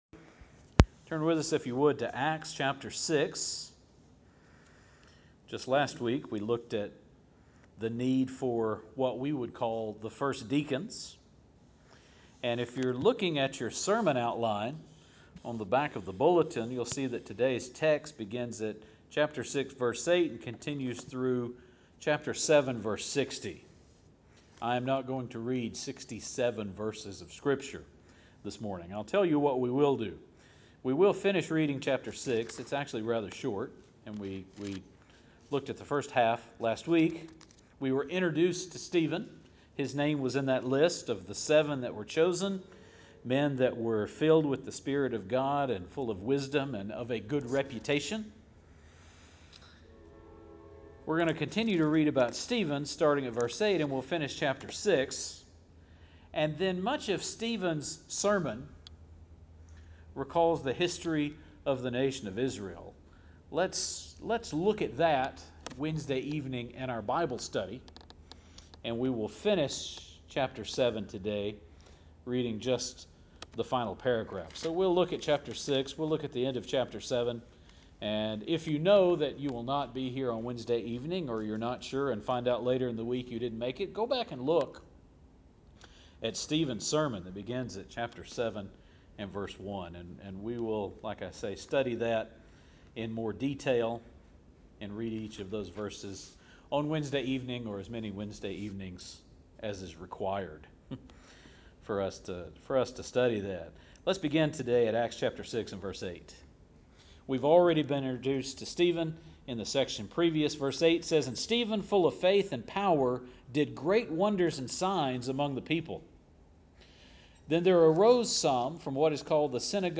Stephen’s Sermon